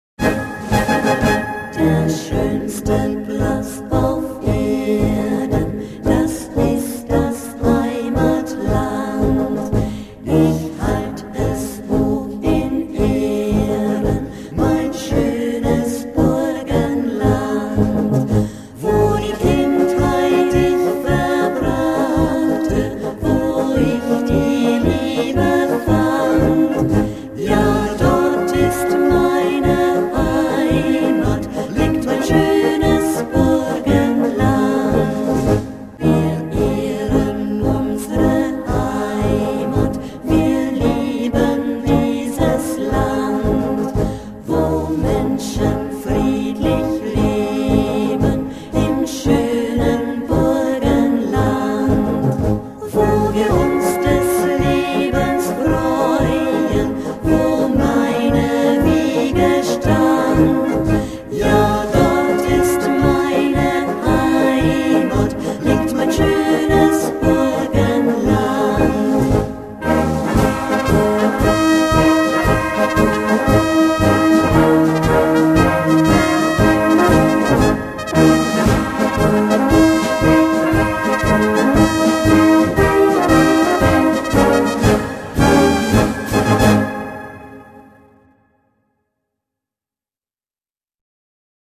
Notenausgaben für Blasorchester, Chor